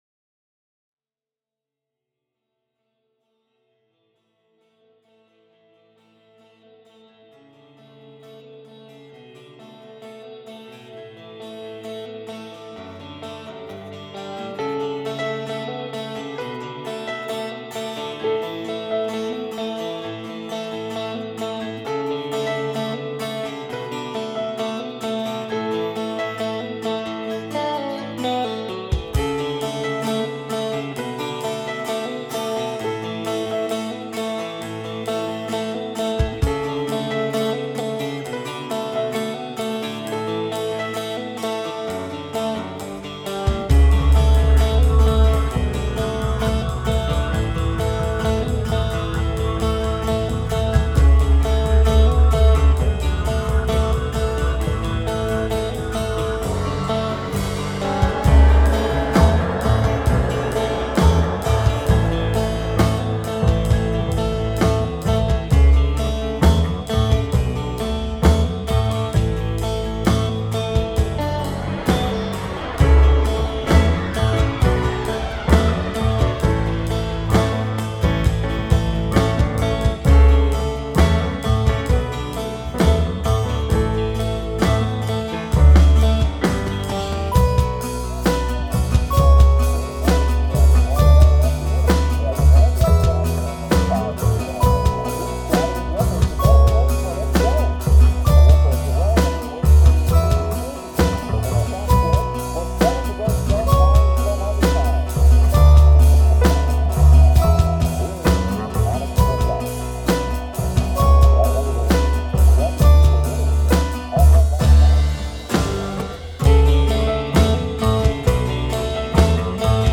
I've been on working on a few mixes in my home studio and could use some feedback.
I played the instruments myself, except for the things I did using Reason.